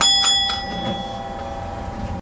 「짤랑」하는 발차 신호음(7000형 차내에서 녹음)